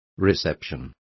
Complete with pronunciation of the translation of reception.